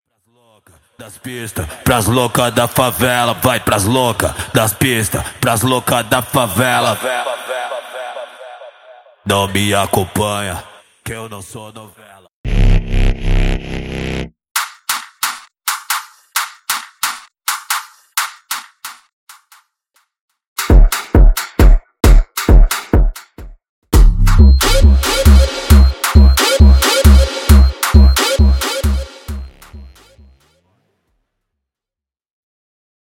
(Backing Vocals)
(Instrumental)
(Leading Vocals)
(Percussion & Drums Stem)